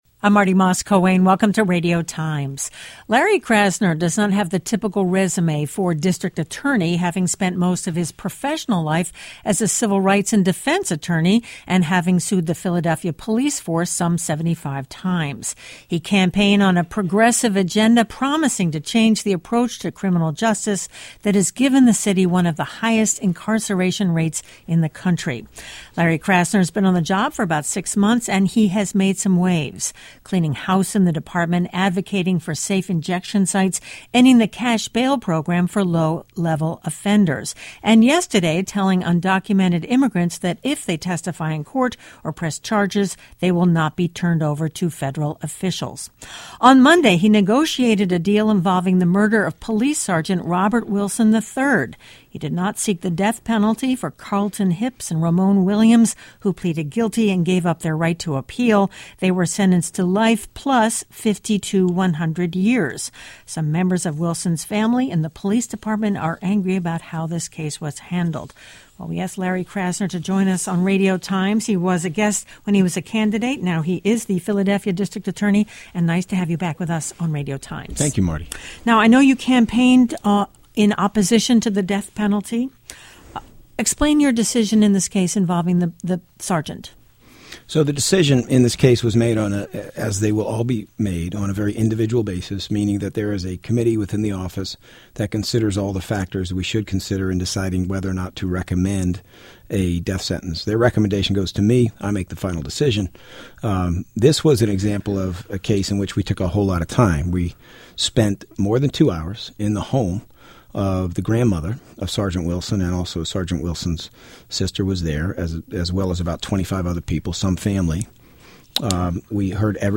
Democratic candidate for District Attorney of Philadelphia, LARRY KRASNER joins us today.
Brought to you by Radio Times Radio Times WHYY's Radio Times is an engaging and timely call-in program that tackles wide-ranging issues of concern to listeners in the Delaware Valley.